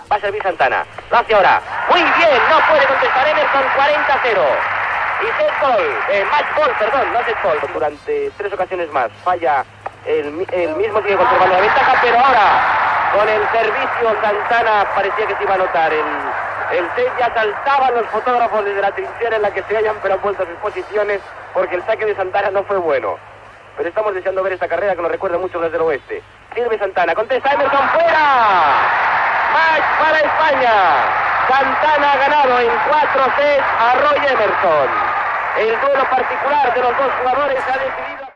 Transmissió, des d'Austràlia, del partit de la Copa Davis de Tennis entre Roy Emerson i Manolo Santana, qui guanya el matx.